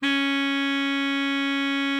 bari_sax_061.wav